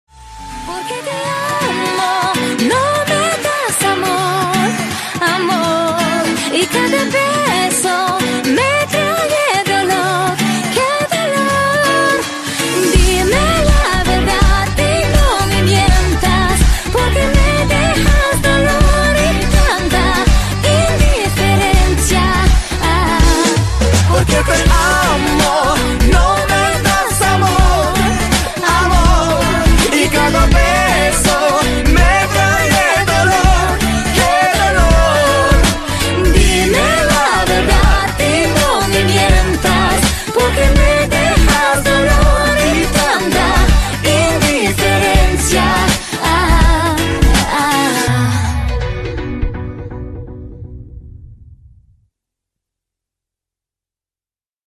• Качество: 128, Stereo
поп
громкие
женский вокал
дуэт
мужской и женский вокал